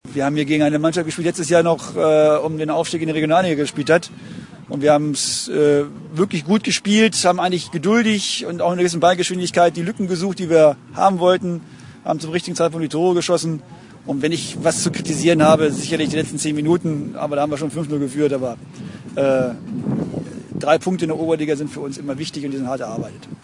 Statement